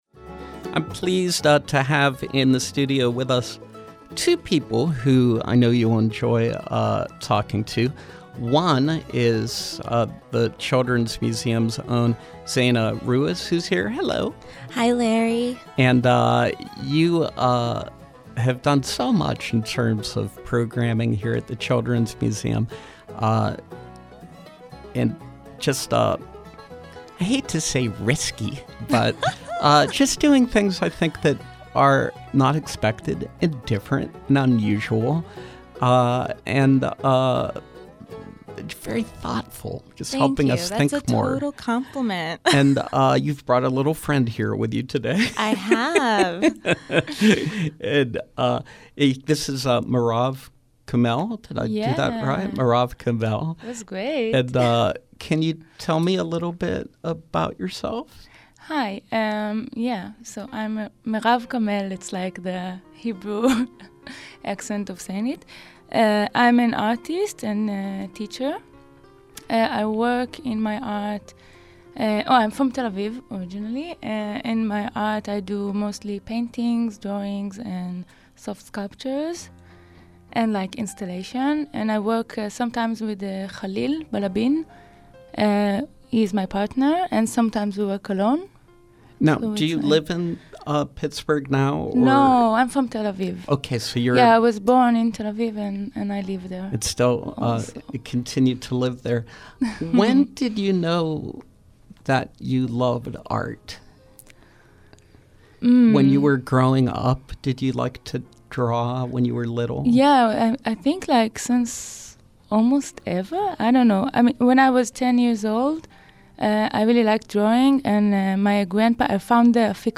In Studio Pop-Up
Interviews